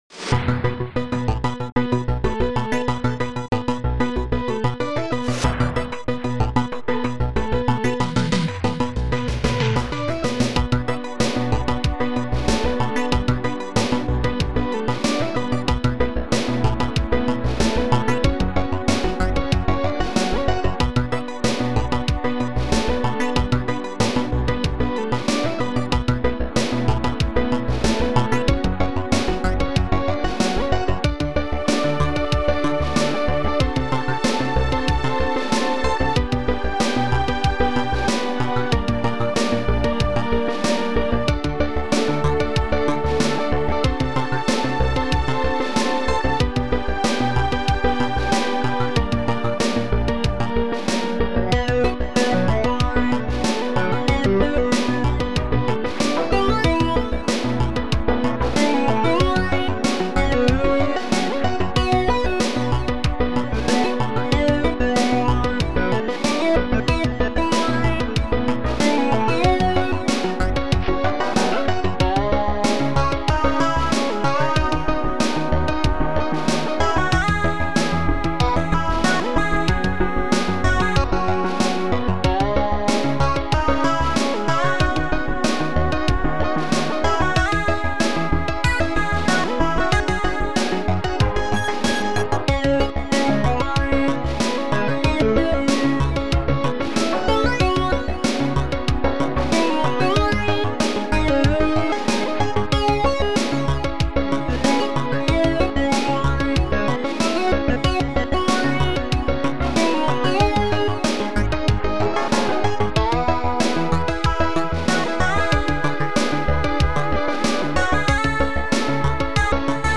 Protracker Module
2 channels